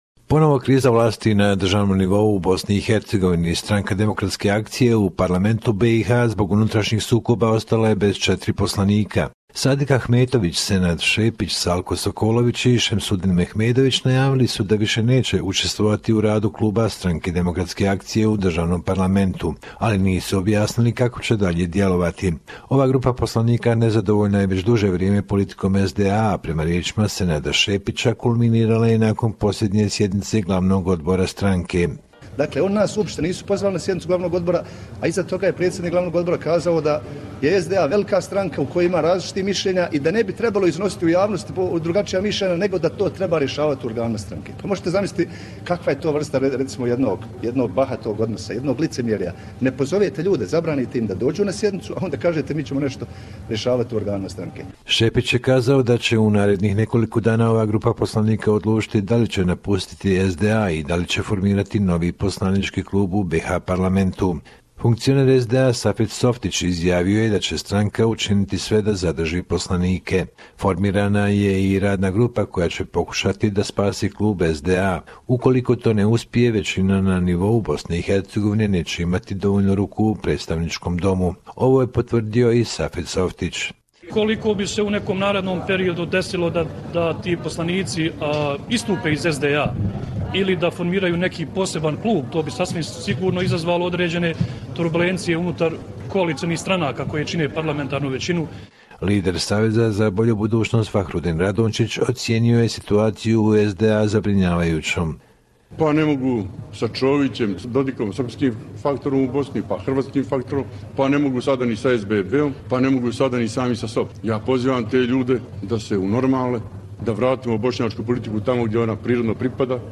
Report from Bosnia and Herzegovina Dec 16, 2016